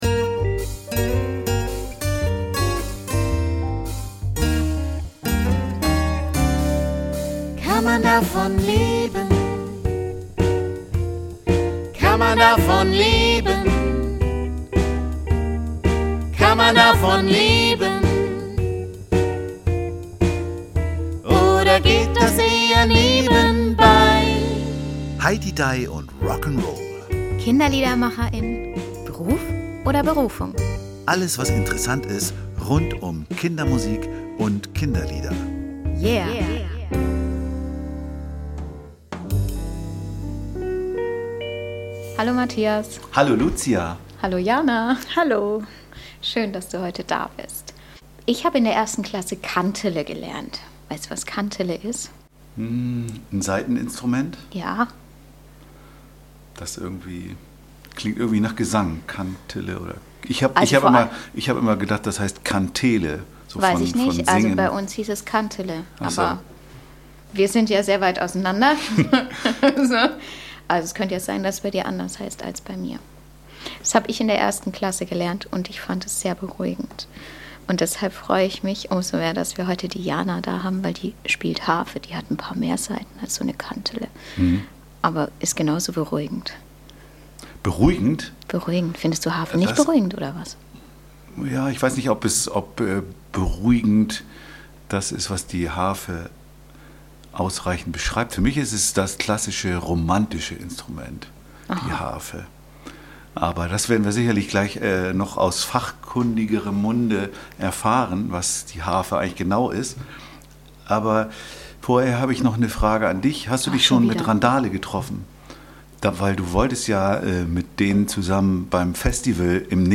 Und natürlich lässt sie auch eine Kostprobe ihres Könnens da!
eine-harfenrundfahrt-im-liedergarten-mmp.mp3